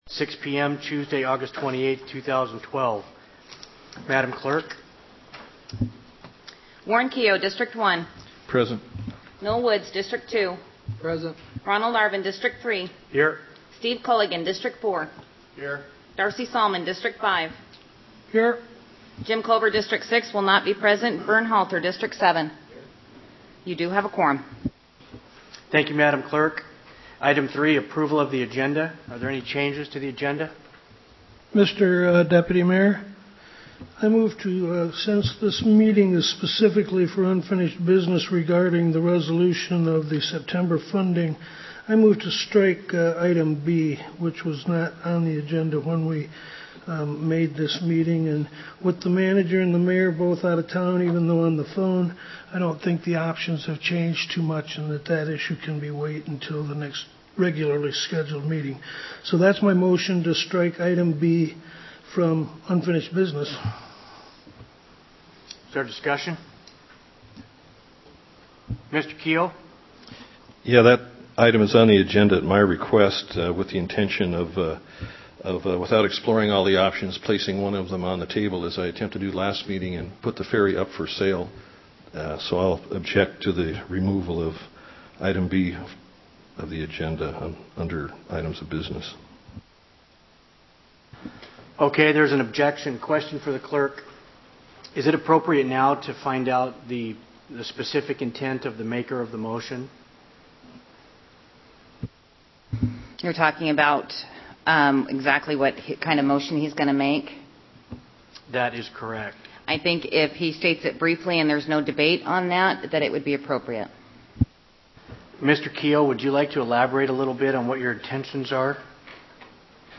Special Assembly Meeting on the Ferry